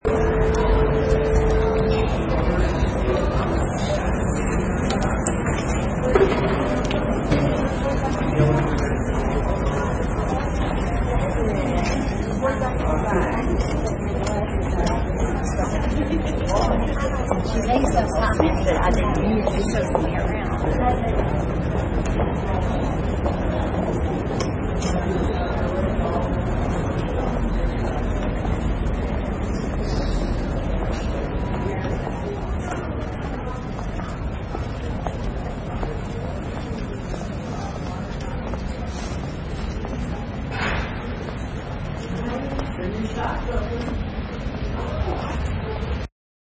Sound recording: Hospital Ambience 2
Backround sounds of a hospital including nurse chatter and equipment noises
Product Info: 48k 24bit Stereo
Category: Ambiences/Backgrounds / Hospitals
Try preview above (pink tone added for copyright).
Hospital_Ambience_2.mp3